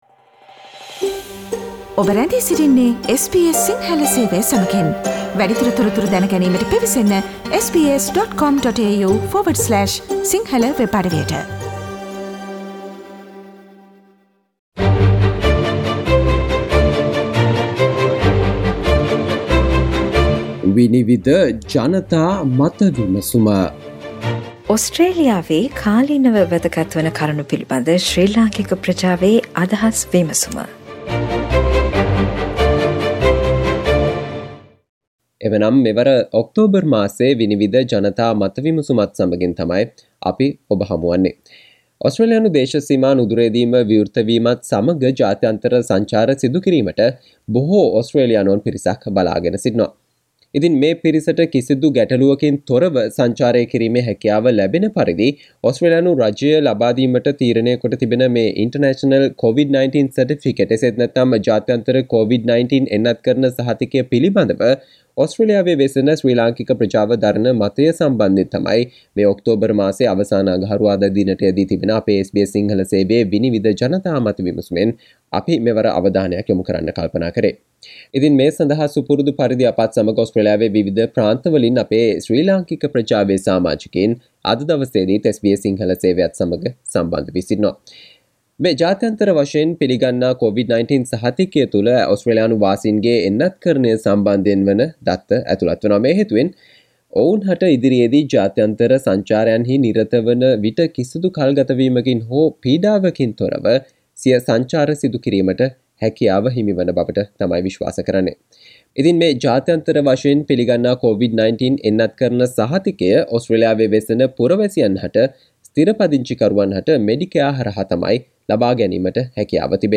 ඕස්ට්‍රේලියානු ජාත්‍යන්තර දේශසීමා විවෘත කිරීමත් සමග ජාත්‍යන්තර සංචාර ගැටලුවකින් තොරව සිදු කිරීමට ඕස්ට්‍රේලියානු රජය ලබාදෙන International COVID-19 vaccination certificate එක පිලිබඳ ඕස්ට්‍රේලියාවේ ශ්‍රී ලංකික ප්‍රජාවේ අදහස් වලට සවන් දෙන්න මෙම ඔක්තෝබර් මාසයේ SBS සිංහල ගුවන් විදුලියේ 'විනිවිද' ජනතා මත විමසුම තුලින්